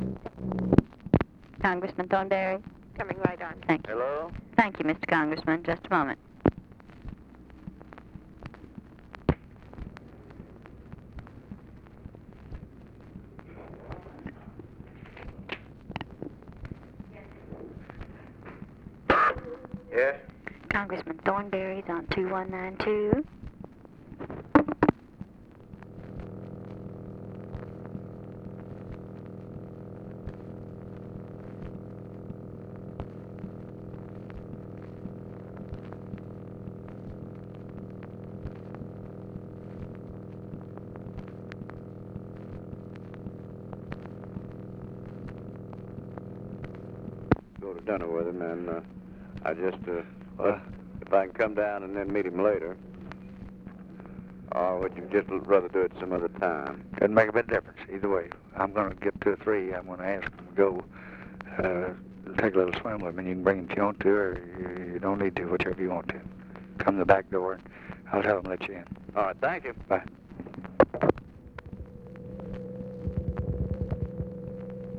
Conversation with HOMER THORNBERRY, December 5, 1963
Secret White House Tapes